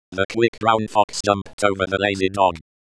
eSpeak 是一款适用于 Linux 和 Windows 的紧凑型开源软件语音合成器，适用于英语和其他语言。
文字转语音样本：
它非常易于使用，但是像 pyttsx 一样听起来很机器人。
espeak.wav